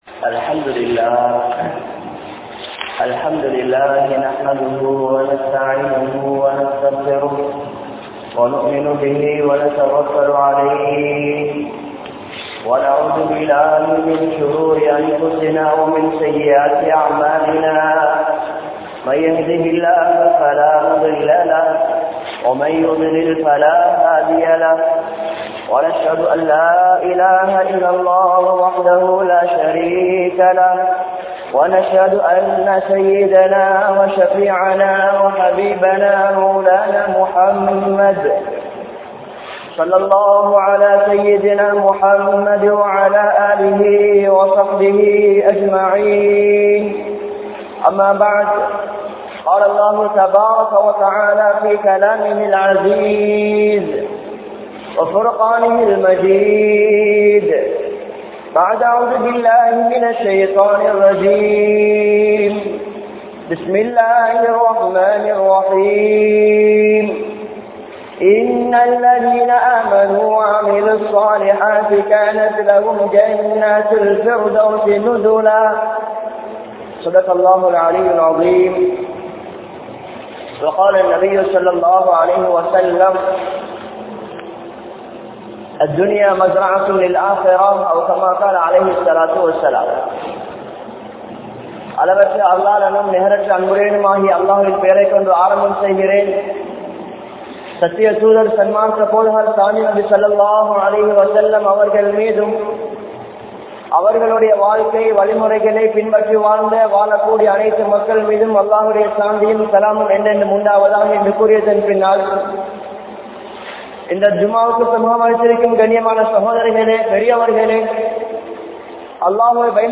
Ramalaanudan Amalhal Mudihiratha? (ரமழானுடன் அமல்கள் முடிகிறதா?) | Audio Bayans | All Ceylon Muslim Youth Community | Addalaichenai